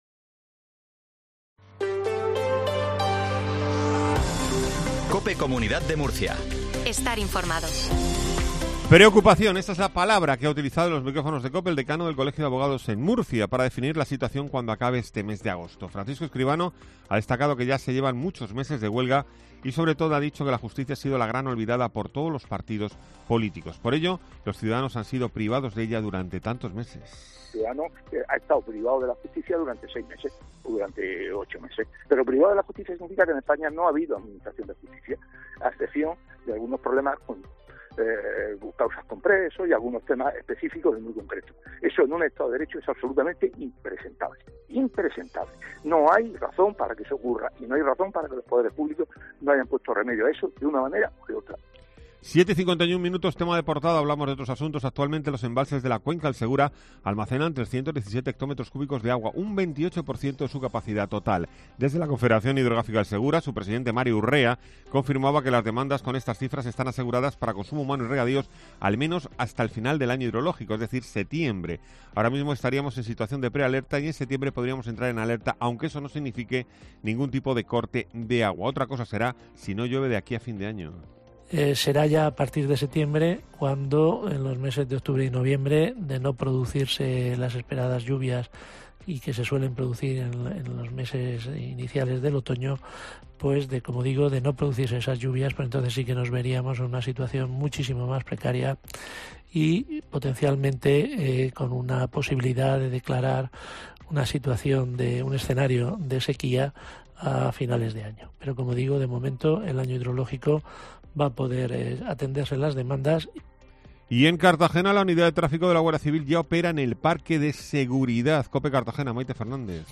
INFORMATIVO MATINAL REGION DE MURCIA 0750